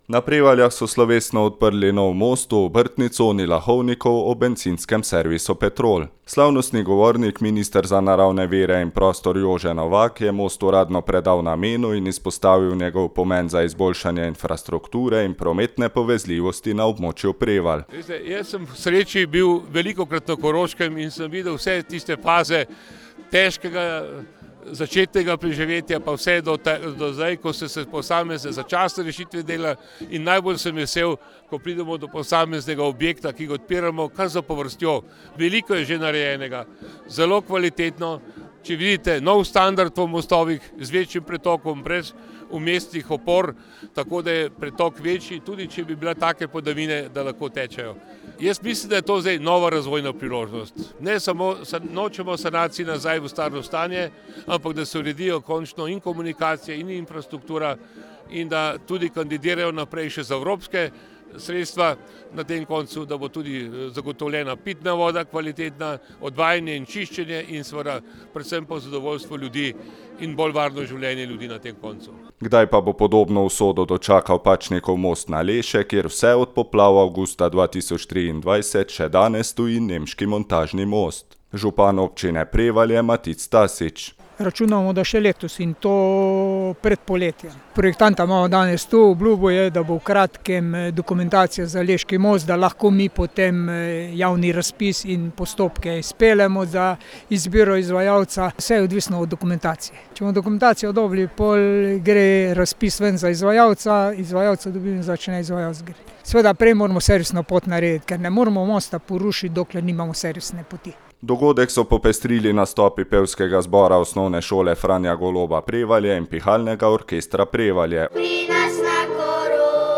Na Prevaljah so slovesno odprli nov most v obrtni coni Lahovnikovo ob bencinskem servisu Petrol.
Slavnostni govornik, minister za naravne vire in prostor Jože Novak, je most uradno predal namenu in izpostavil njegov pomen za izboljšanje infrastrukture in prometne povezljivosti na območju Prevalj.